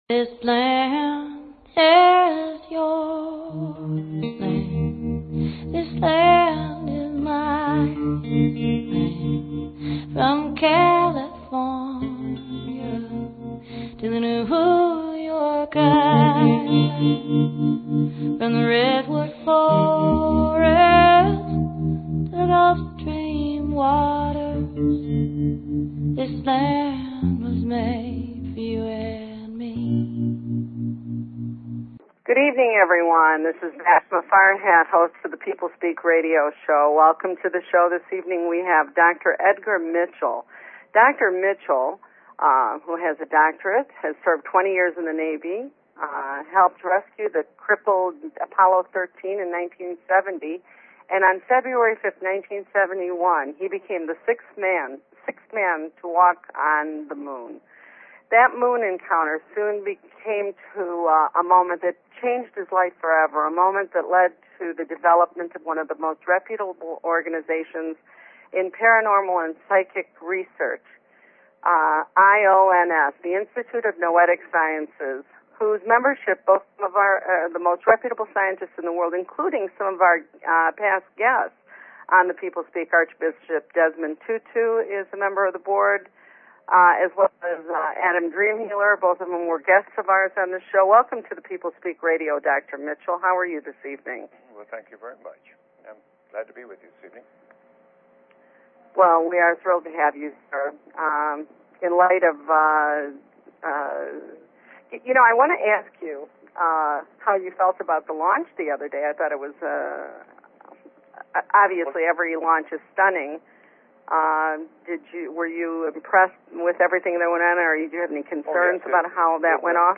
Guest, Edgar Mitchell